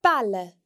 The non-slender L sounds much like the English ‘L’ sound, and can be heard in baile (a town):